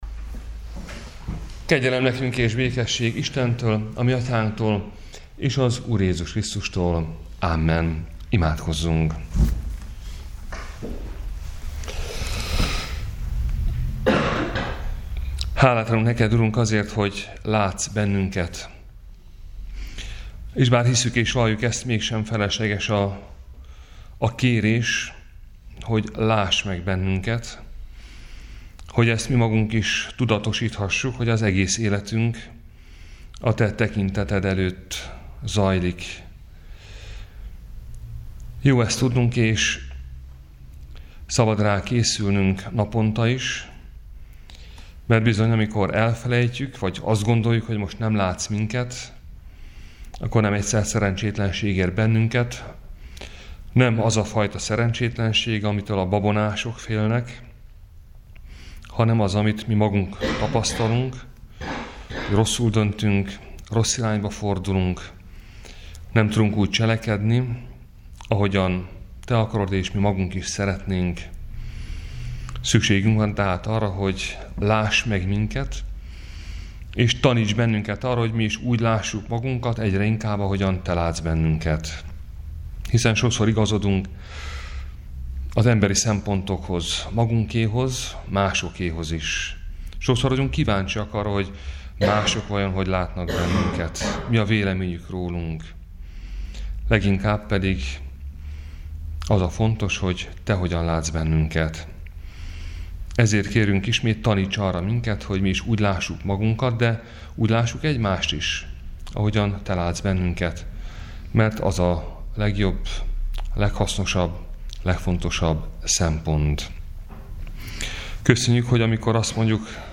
Áhítat, 2019. május 15.